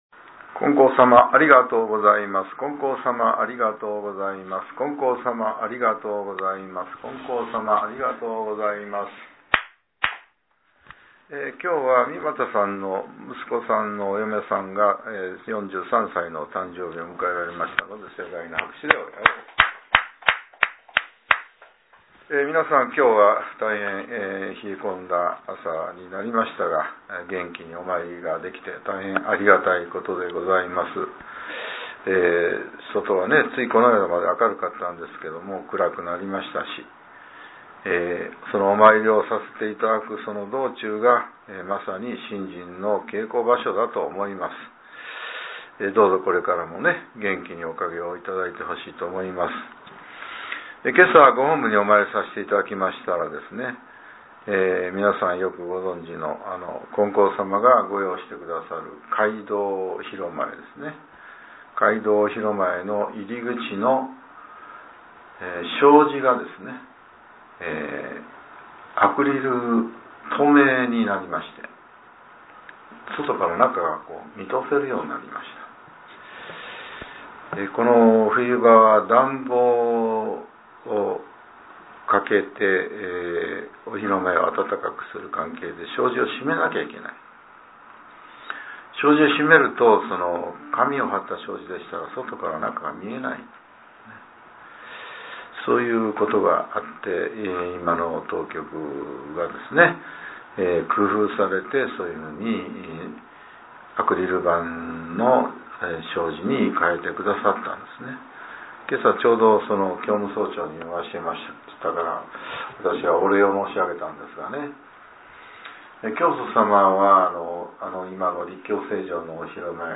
令和６年１１月８日（朝）のお話が、音声ブログとして更新されています。